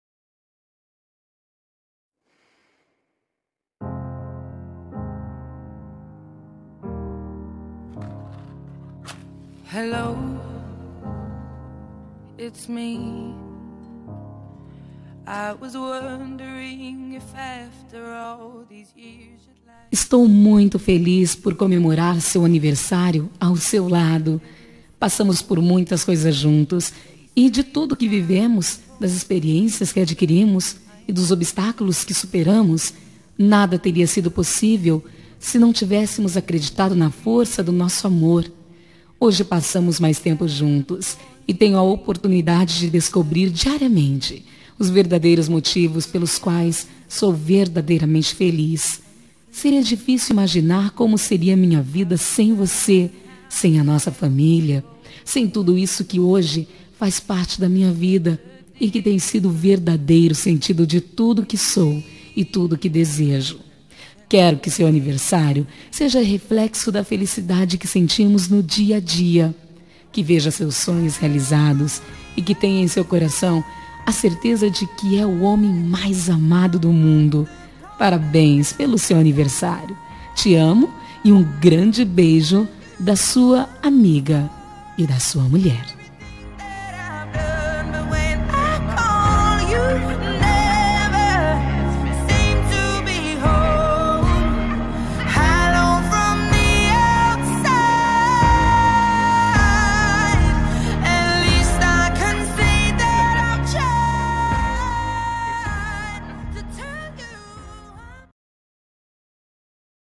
Telemensagem de Aniversário de Marido – Voz Feminina – Cód: 1144 – Linda